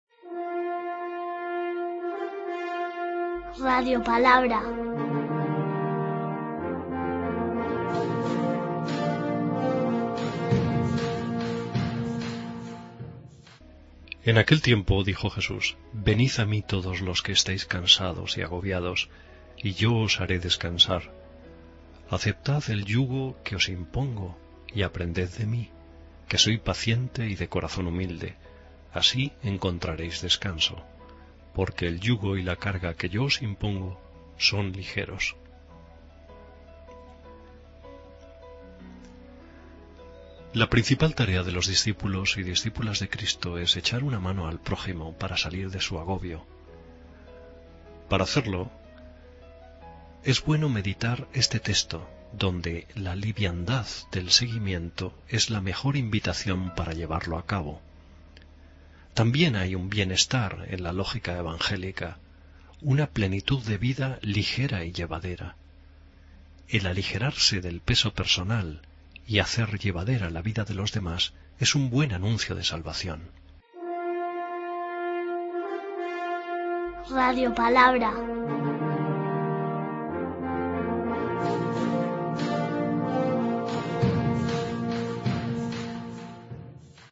Lectura del libro de Isaías 40,25-31